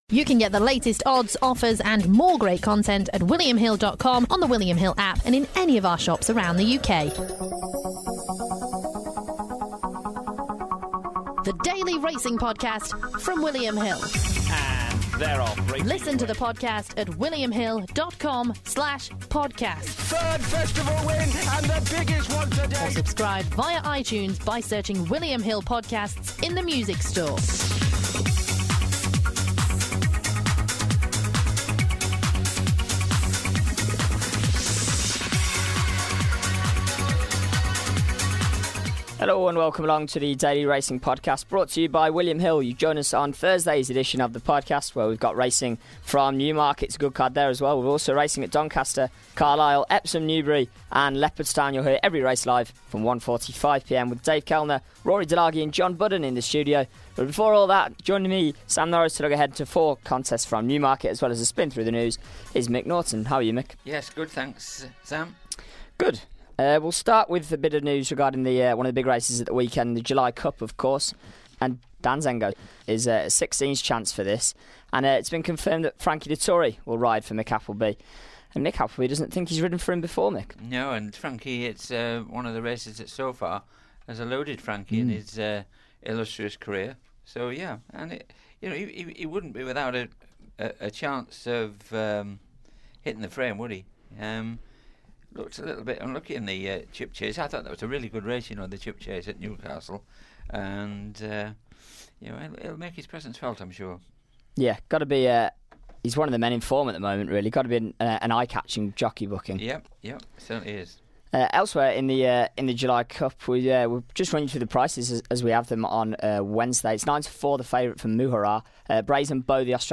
The pair also discuss the latest racing news.